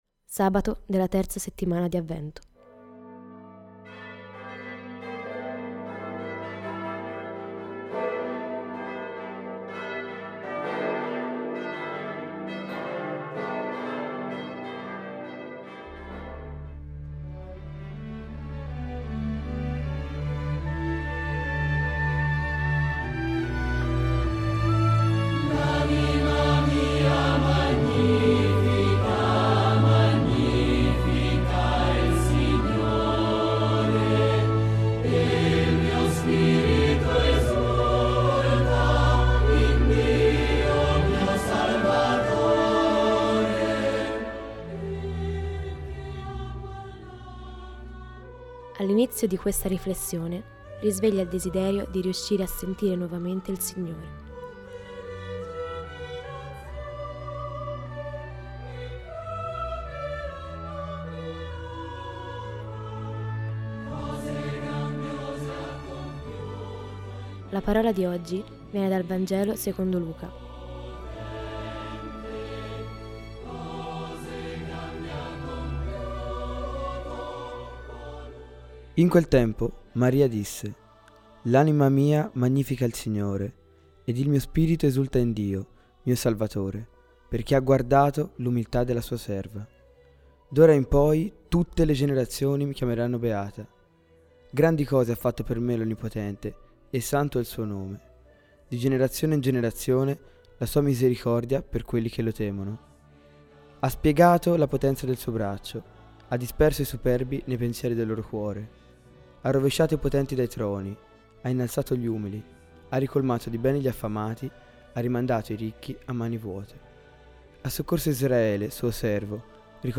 Musica di Marco Frisina: Canto del Magnificat